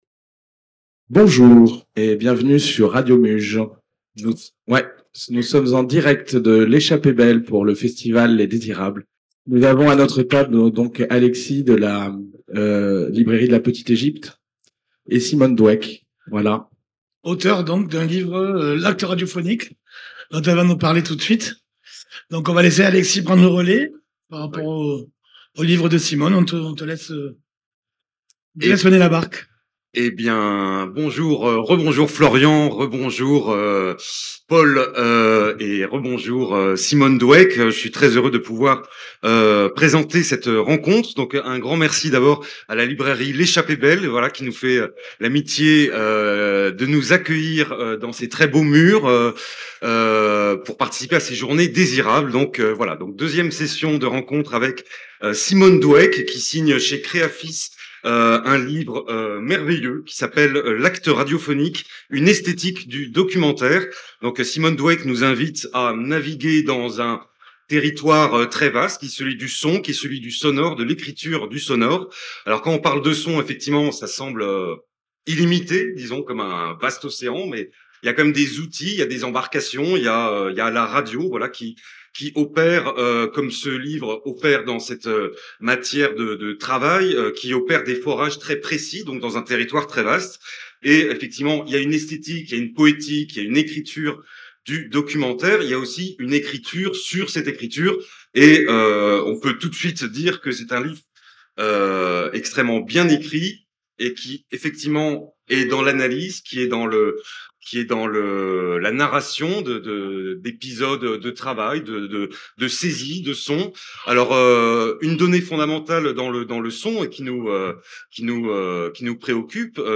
ITW